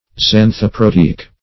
Search Result for " xanthoproteic" : The Collaborative International Dictionary of English v.0.48: Xanthoproteic \Xan`tho*pro*te"ic\, a. (Physiol.
xanthoproteic.mp3